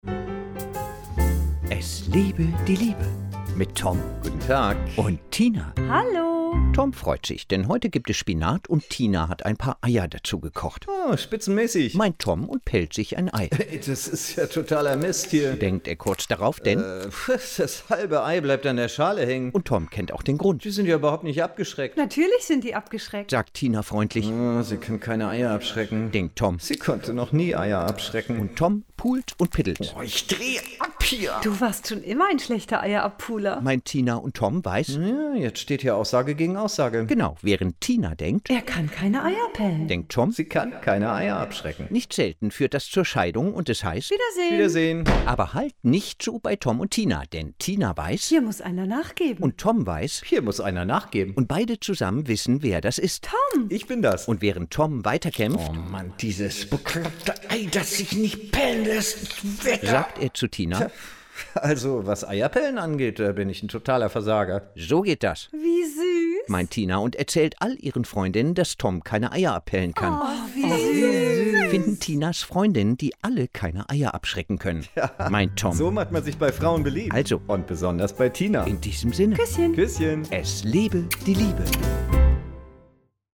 Hörfunkserie
Die Radio-Comedy bekannt vom WDR, NDR, SWR und HR.